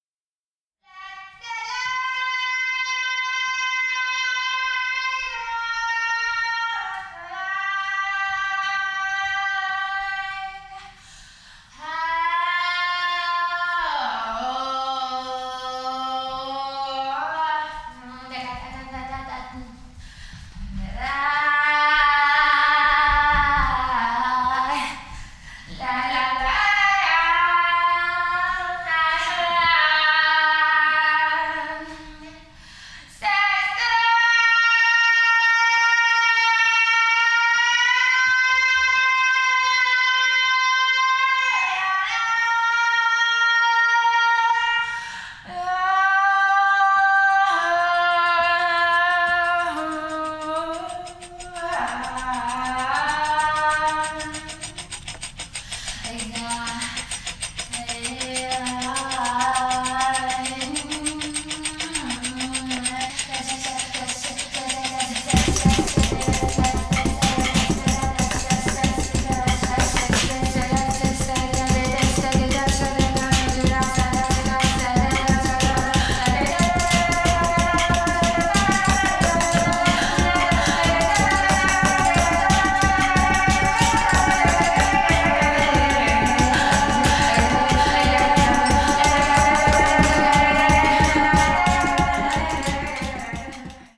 シャーマニックで土着的な開放感に満ちた傑作！
ヴォイス・パフォーマー、そして、サウンド・アーティストとして、優れた直観力と洞察力を持つ2人ならではの1枚！